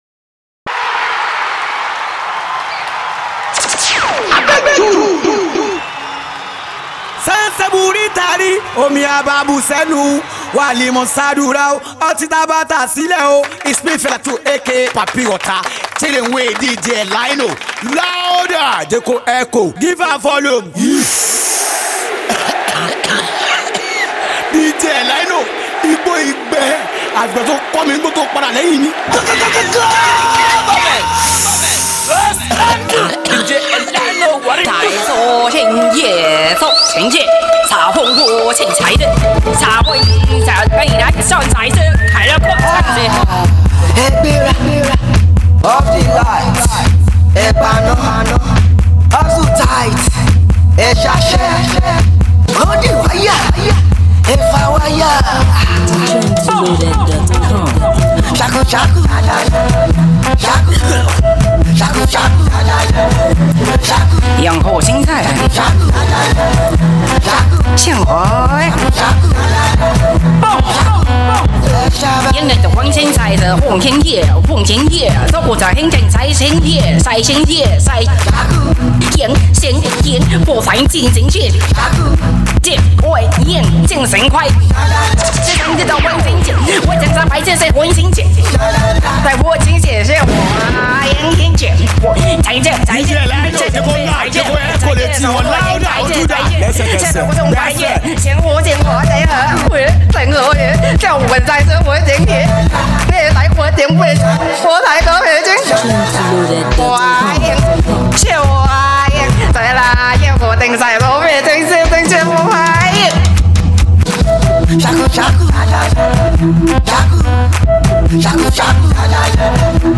The Hottest Street Songs